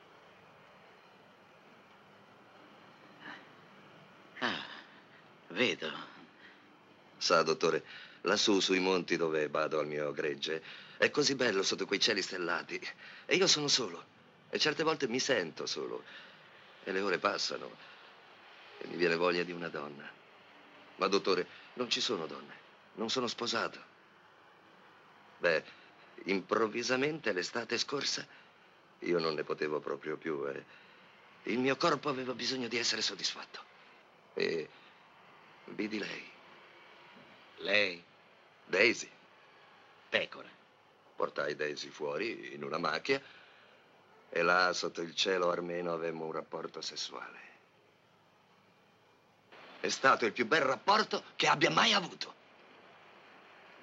nel film "Tutto quello che avreste voluto sapere sul sesso*(*ma non avete mai osato chiedere)", in cui doppia Titos Vandis.